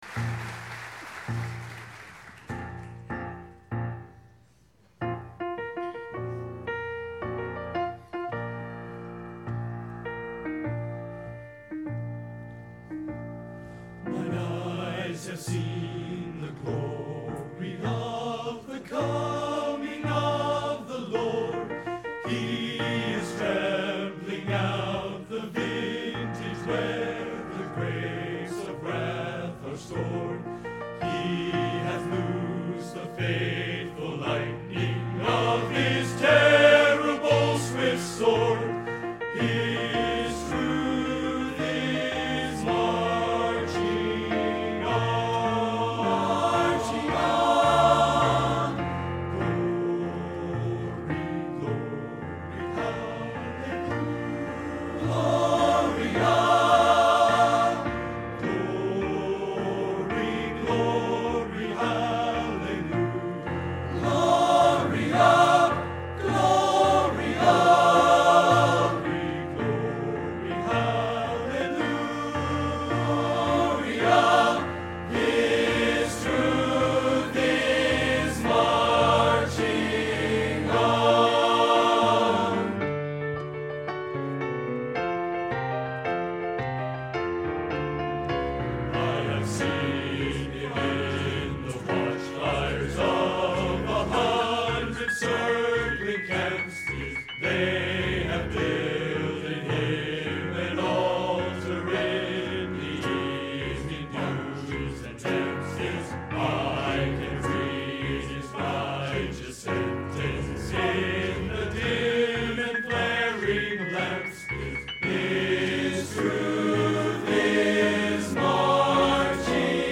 Genre: Patriotic | Type: